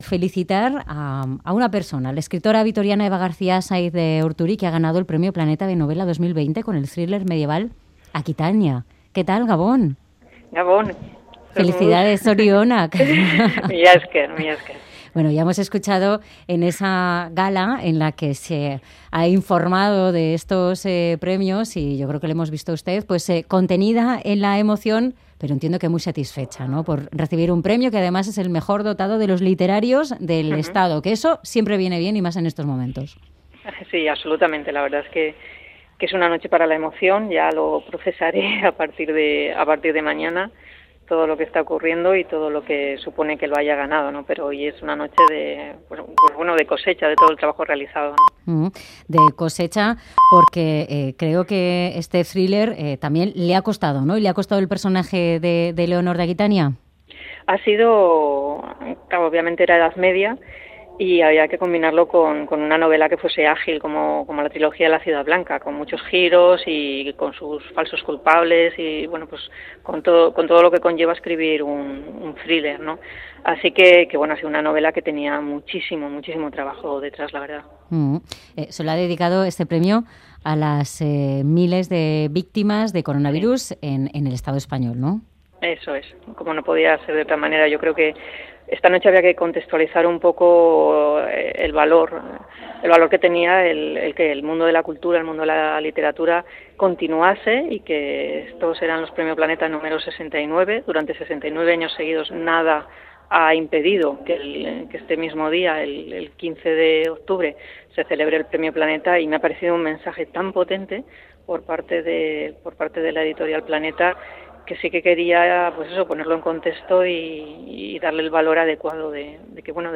Audio: Entrevistamos en “Ganbara” a la escritora Eva García Sáenz de Urturi poco después de ser galardonada con el 69 Premio Planeta por la novela "Aquitania".